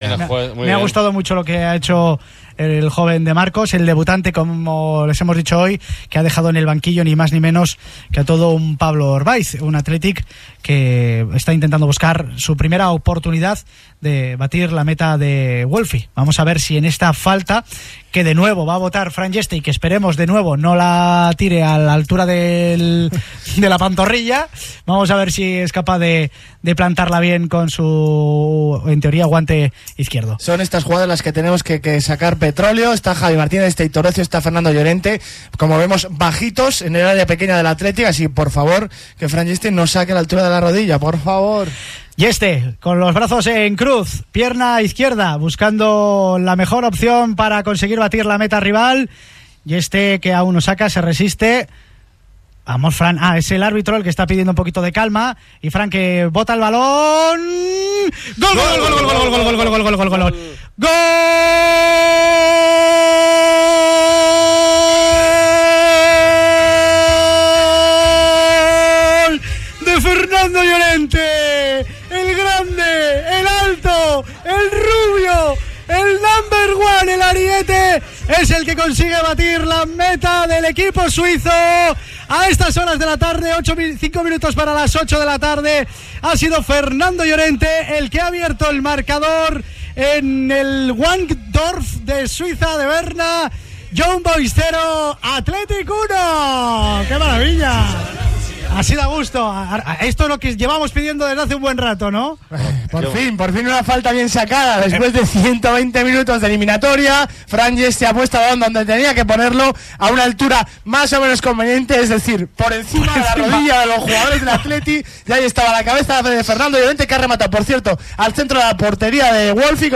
Así retransmitimos el debut de Óscar de Marcos en Radio Nervión